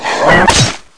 robostab.mp3